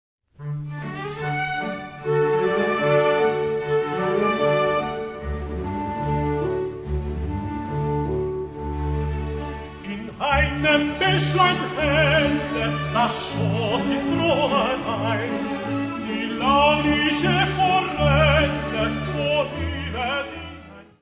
orquestra
tenor
: stereo; 12 cm
Music Category/Genre:  Classical Music
Dó bemol M D550.